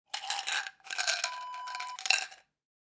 Pills to Hand Wav Sound Effect
Description: The sound of pouring a few pills into hand
Properties: 48.000 kHz 24-bit Stereo
A beep sound is embedded in the audio preview file but it is not present in the high resolution downloadable wav file.
Keywords: pour, pouring, shake out, empty, emptying, pills, rattle, palm, hand, bottle, container
pills-to-hand-preview-1.mp3